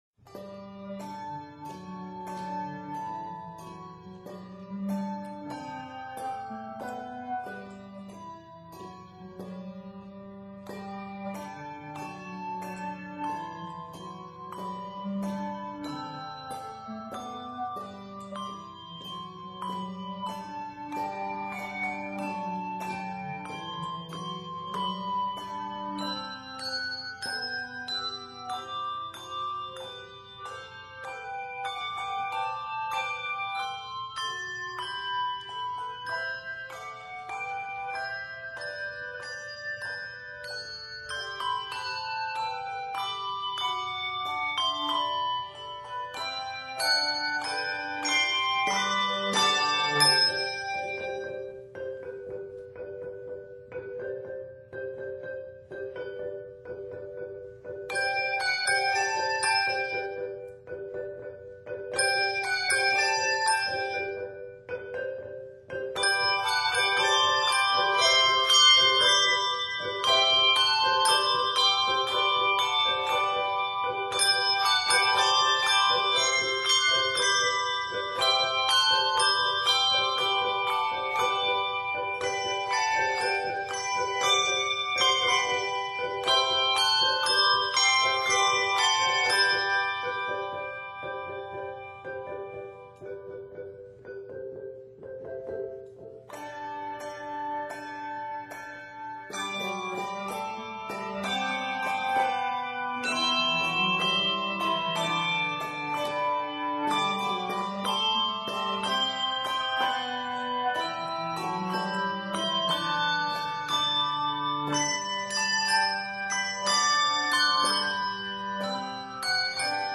plenty of malleting and mixed meter
stirring and upbeat setting